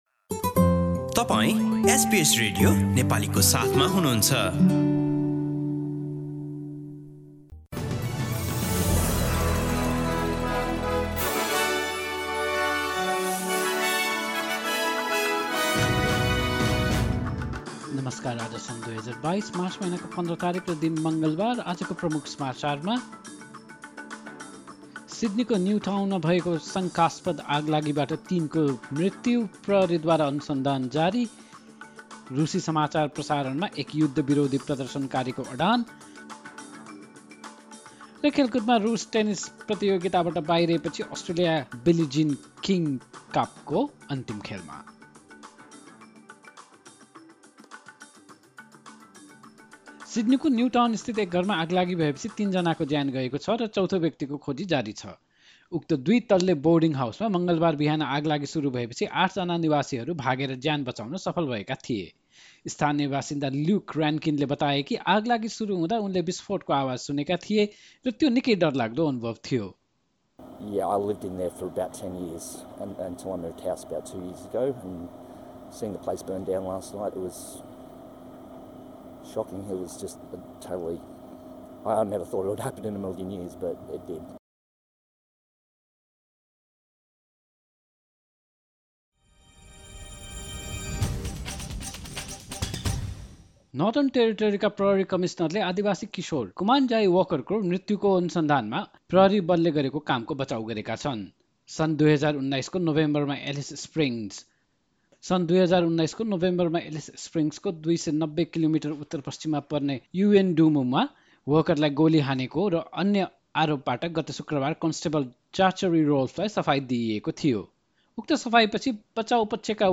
एसबीएस नेपाली अस्ट्रेलिया समाचार: मंगलबार १५ मार्च २०२२